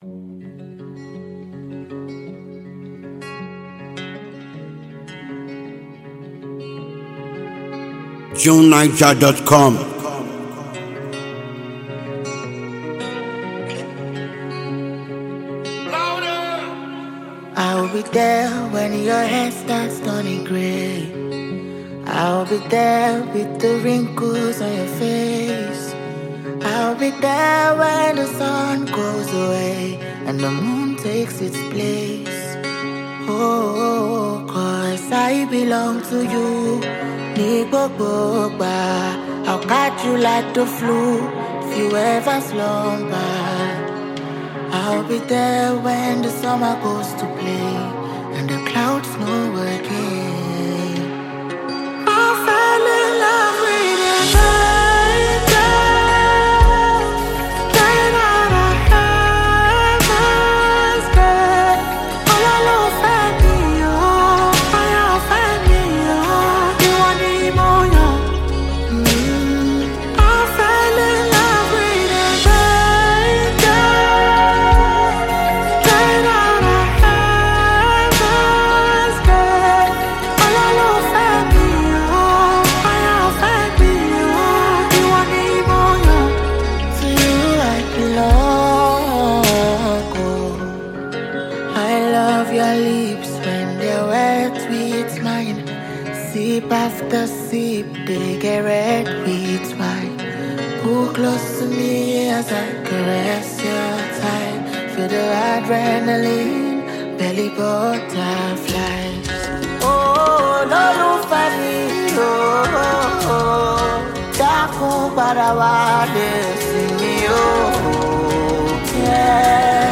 a very creative Nigerian afrobeats singer